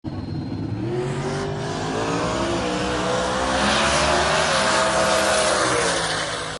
Still one of my favorite videos of the huntin truck man that thing was screaming.